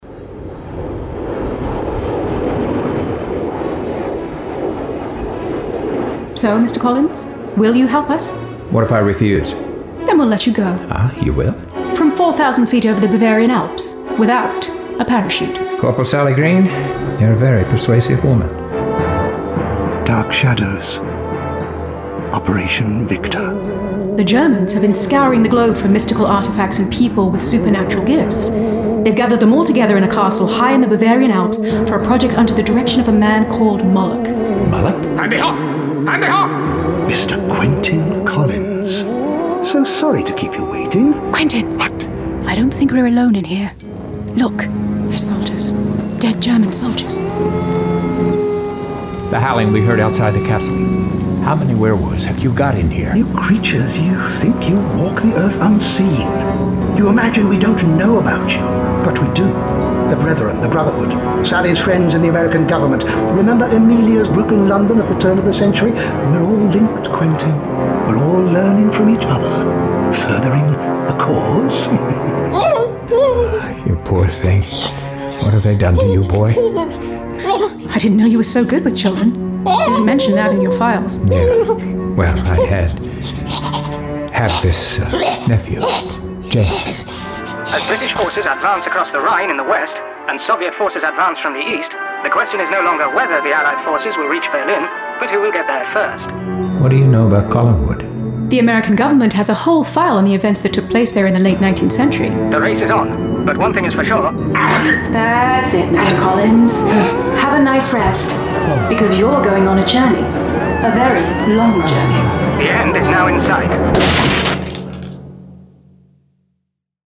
27. Dark Shadows: Operation Victor - Dark Shadows - Dramatised Readings - Big Finish